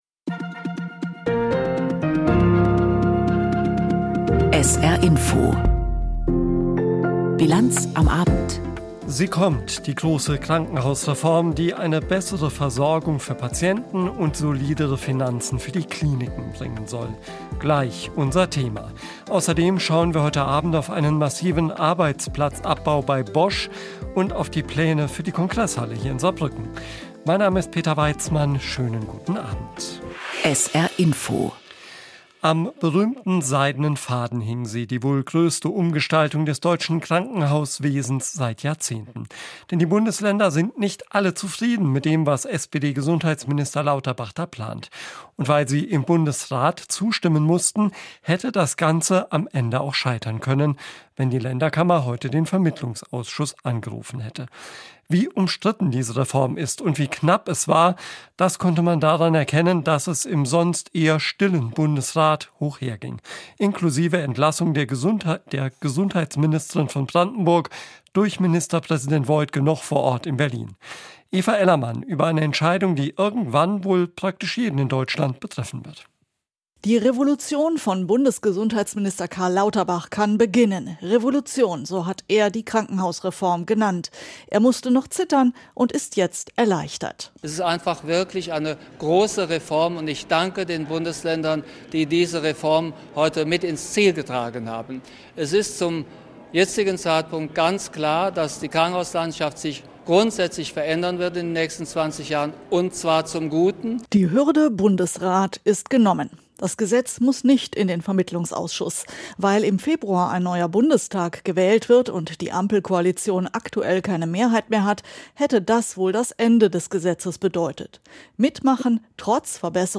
Aktuelles und Hintergründe zu Entwicklungen und Themen des Tages aus Politik, Wirtschaft, Kultur und Gesellschaft in Berichten und Kommentaren.
… continue reading 245 에피소드 # Nachrichten # SR